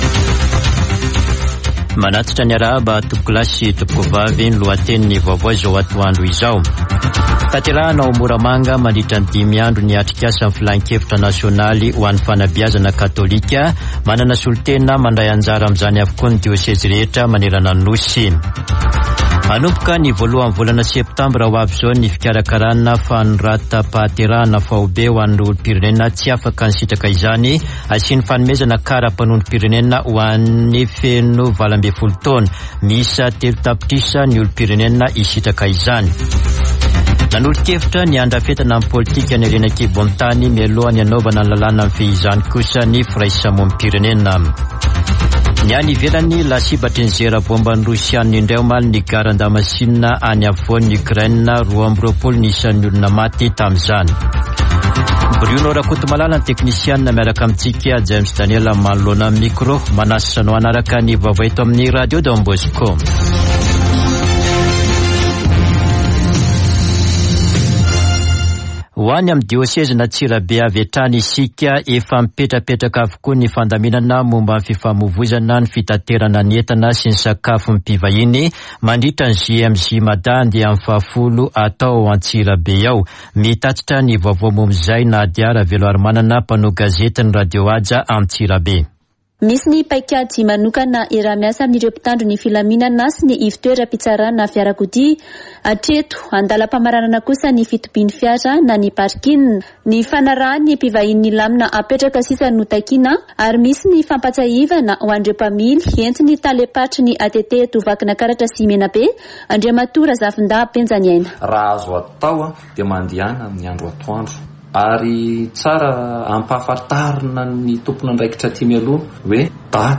[vaovao antoandro] Alakamisy 25 aogositra 2022